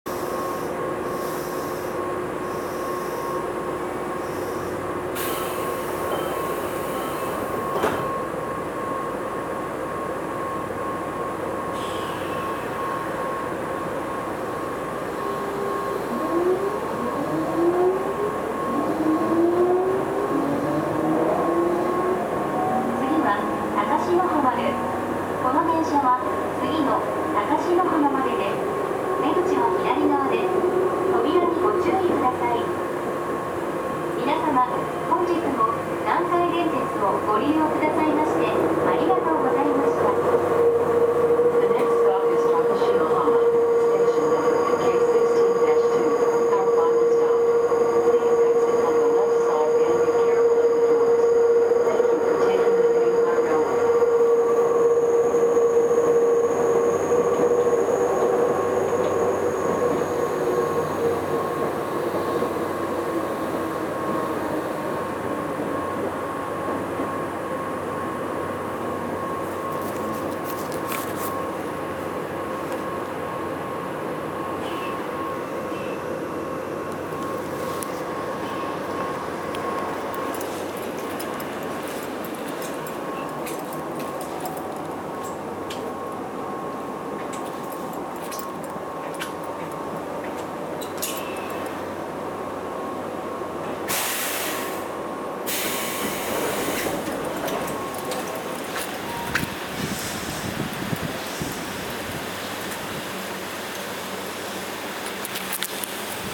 走行機器はGTO素子によるVVVFインバータ制御で、定格100kWのTDK6310-A形かご形三相誘導電動機を制御します。
走行音
録音区間：伽羅橋～高師浜(お持ち帰り)